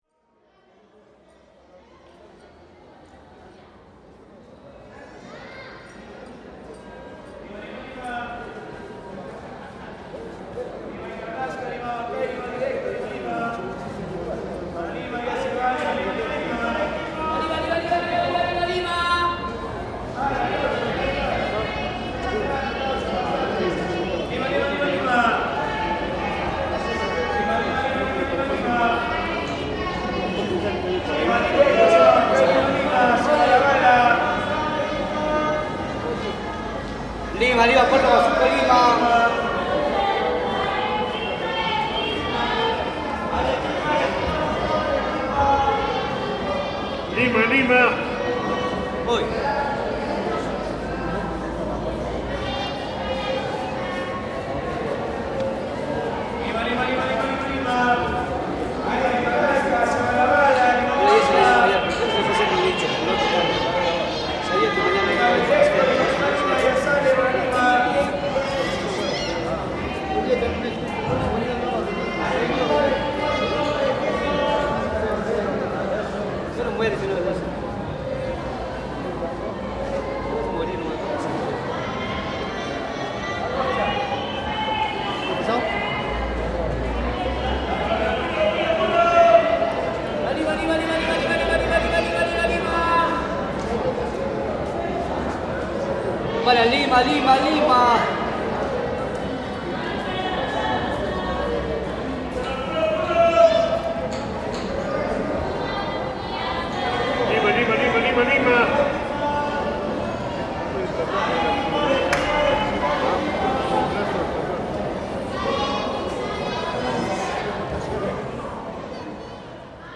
Bus ticket vendors, Cuzco
In the heart of Cusco, the voices of bus ticket vendors create an impromptu symphony. Their melodic calls, each vying for attention, blend into a captivating polyphony that is uniquely Peruvian.